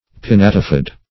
Pinnatifid \Pin*nat"i*fid\, a. [L. pinnatus feathered + root of